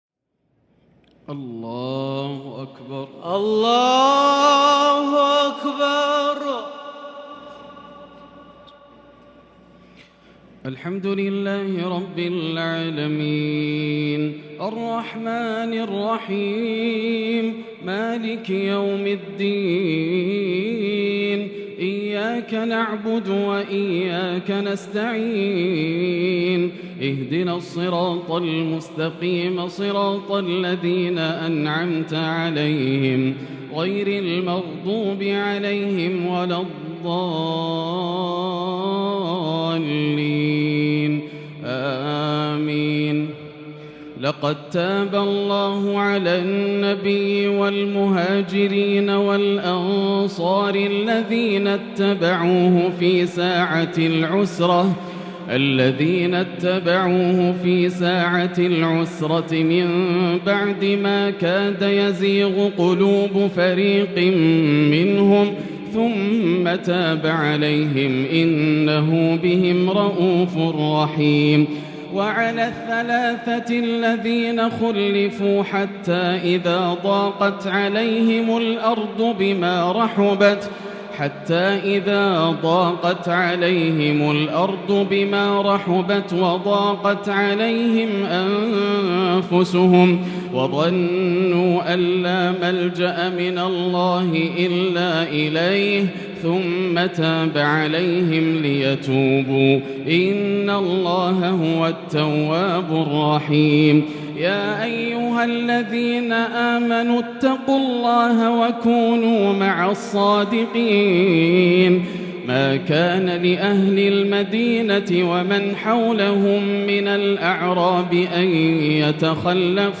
ليلة تأسر الألباب لخواتيم سورة التوبة ومن سورة يونس - ليلة 14 رمضان 1443هـ > الليالي الكاملة > رمضان 1443هـ > التراويح - تلاوات ياسر الدوسري